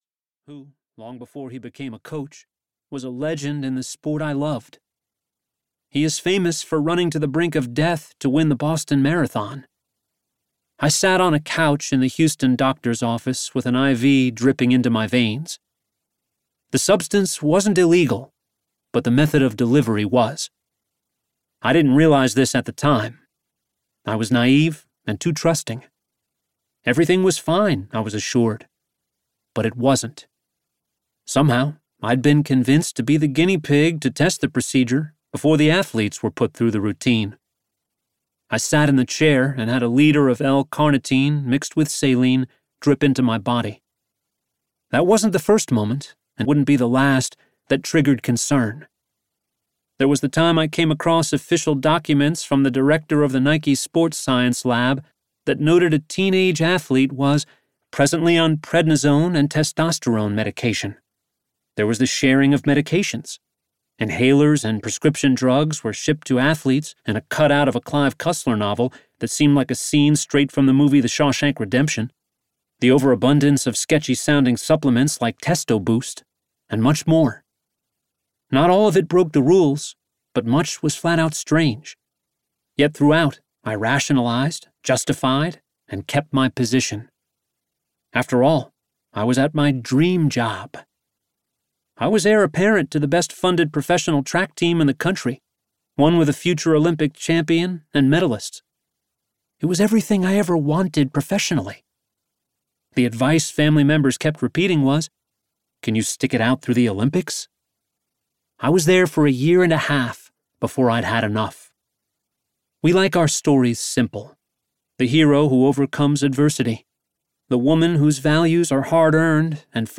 Audiobook Narrator
Nonfiction Samples